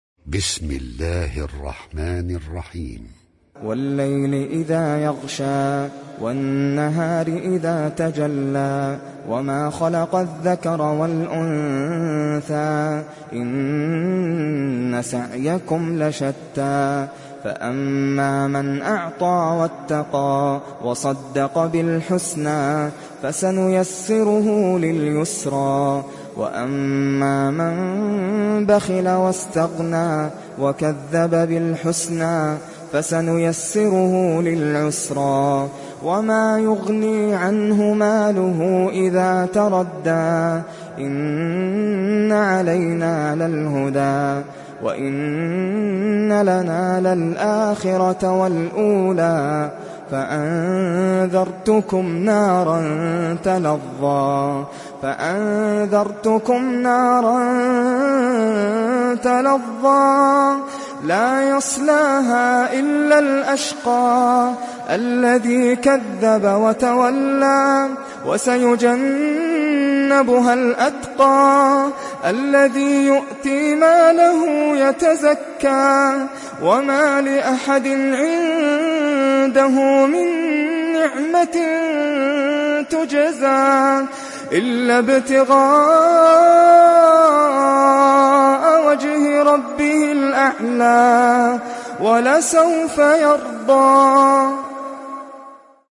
Leyl Suresi İndir mp3 Nasser Al Qatami Riwayat Hafs an Asim, Kurani indirin ve mp3 tam doğrudan bağlantılar dinle